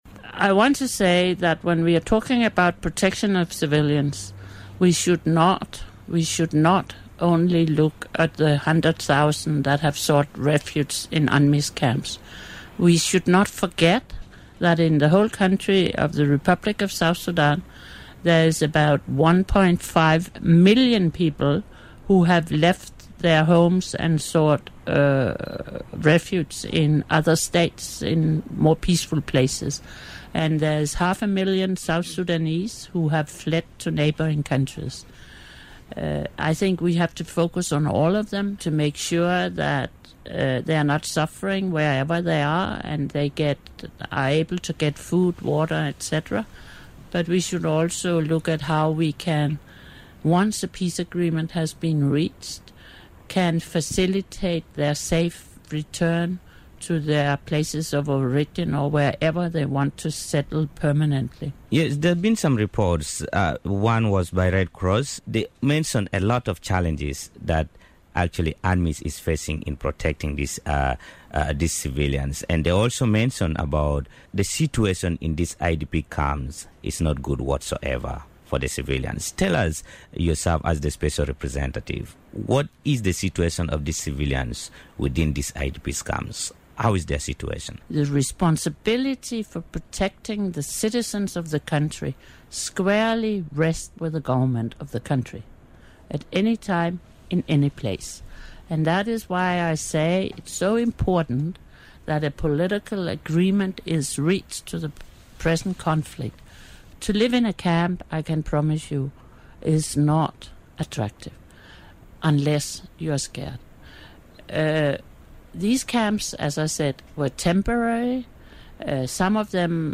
Part of a 45 mins Interview with UNMISS SRSG ON RADIO MIRAYA.